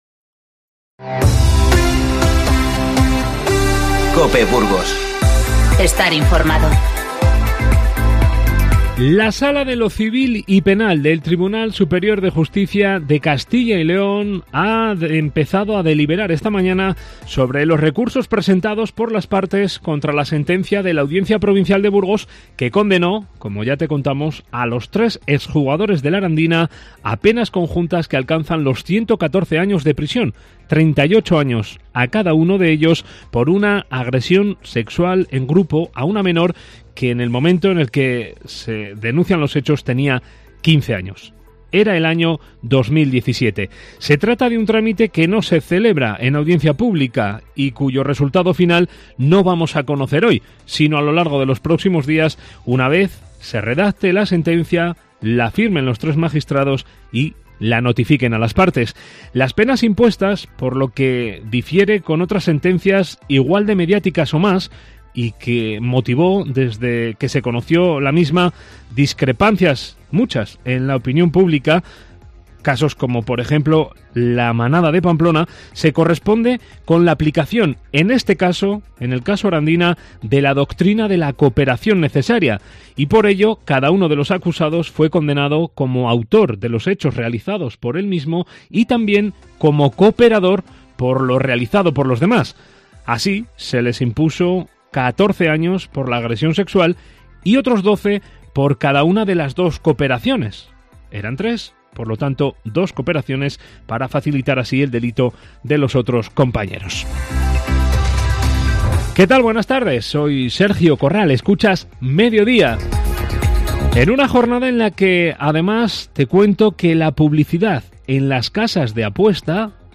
Informativo 27-02-20